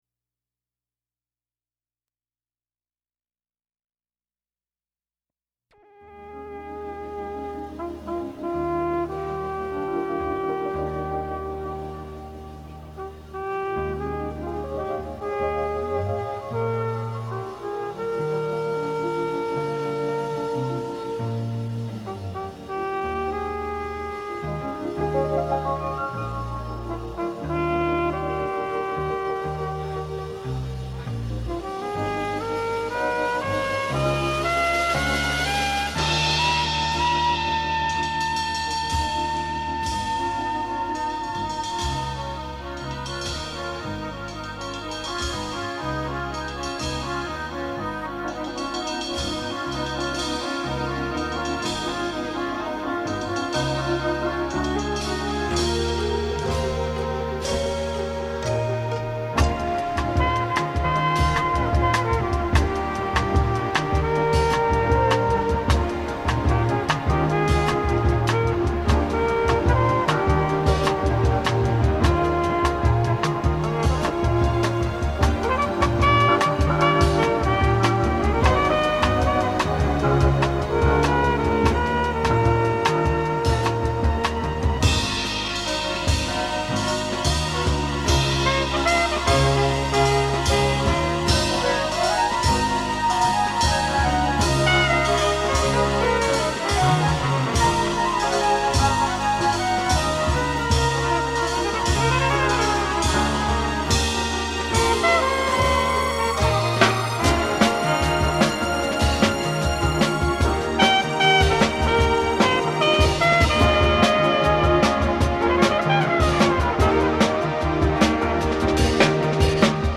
keyboards
trumpet & congas
bass), and two female singers up front.